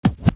For The Record Rub.wav